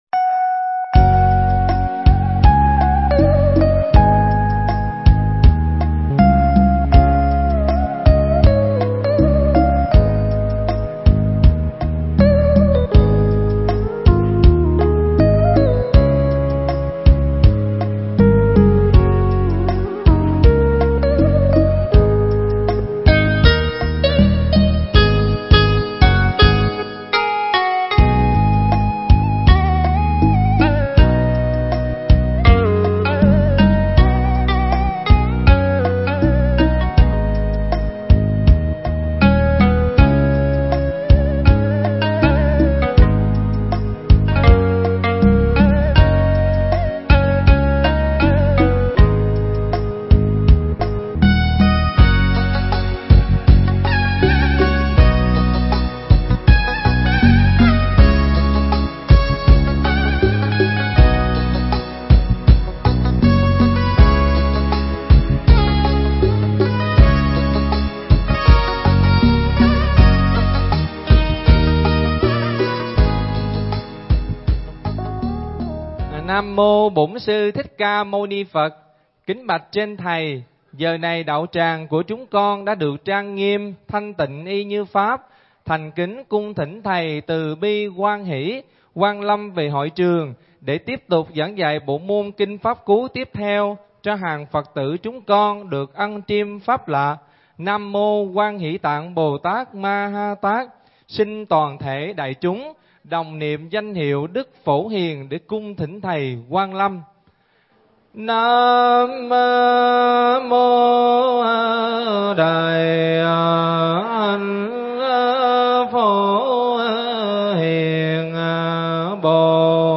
Mp3 Thuyết Giảng Kinh Pháp Cú Phẩm Pháp Trụ Câu 266 Đến 272
giảng nhân lễ sám hối thường kỳ tại Tu Viện Tường Vân